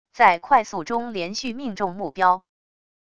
在快速中连续命中目标wav音频